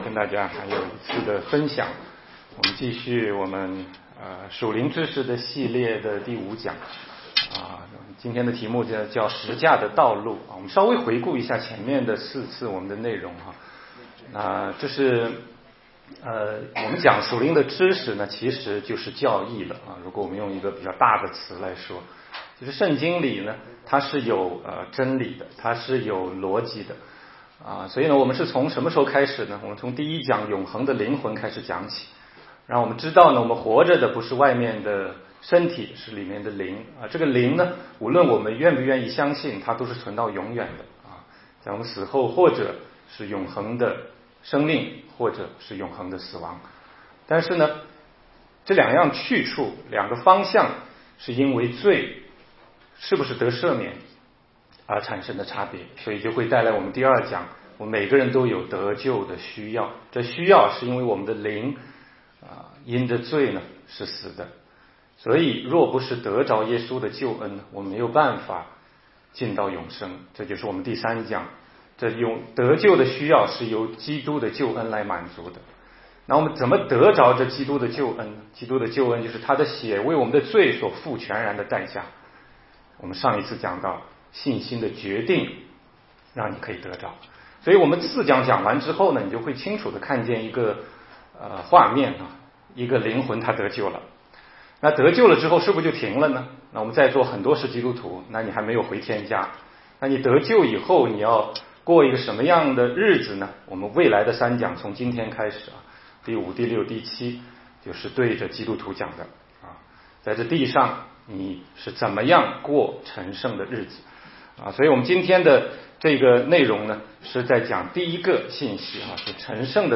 16街讲道录音 - 属灵知识系列之五：十架的道路